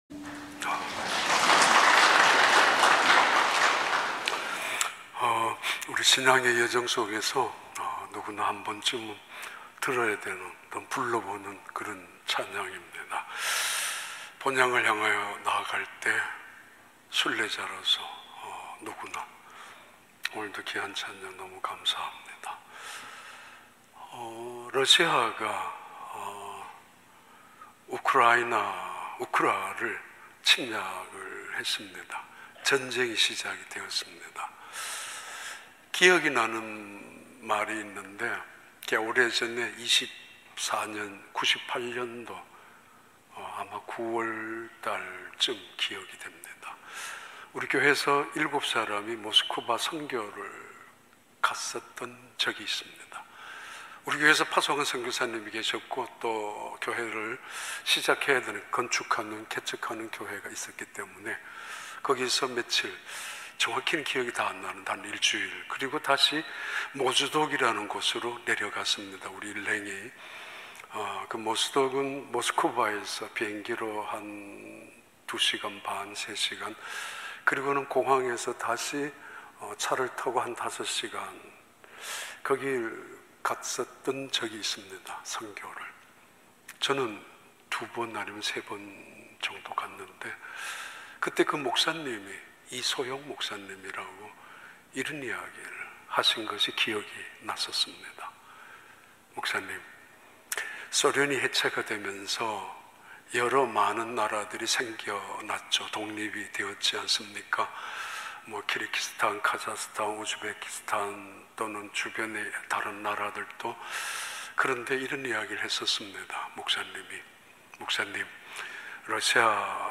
2022년 2월 27일 주일 3부 예배